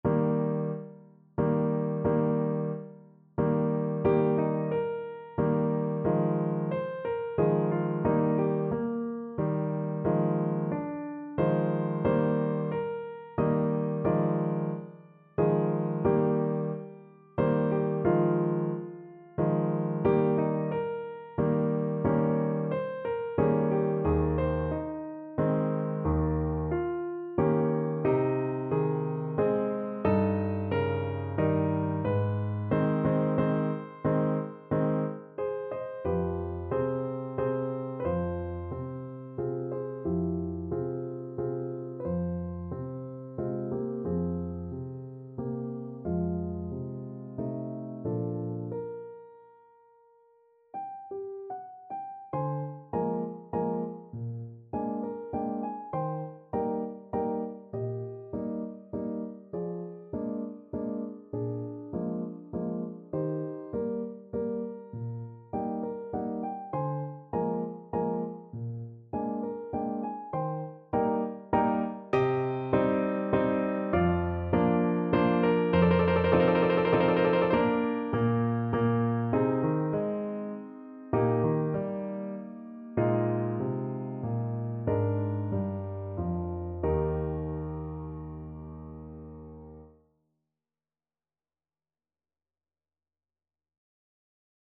Piano version
No parts available for this pieces as it is for solo piano.
~ = 90 Allegretto moderato
3/4 (View more 3/4 Music)
Instrument:
Classical (View more Classical Piano Music)